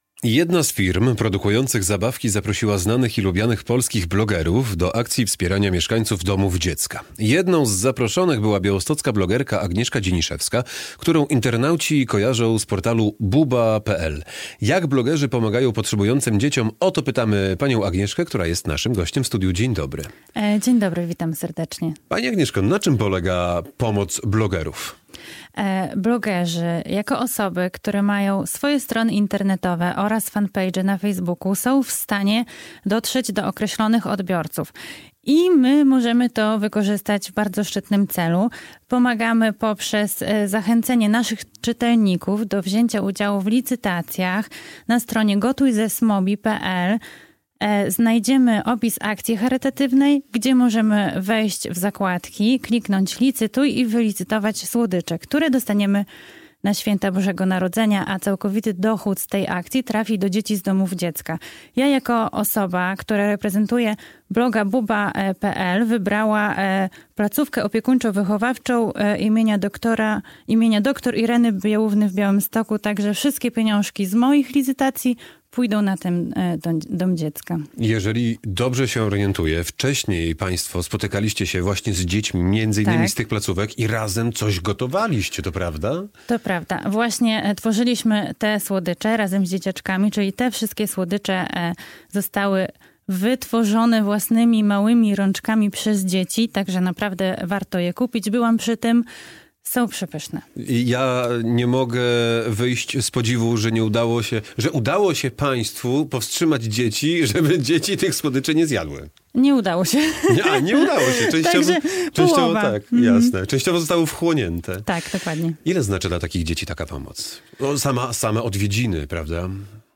białostocka blogerka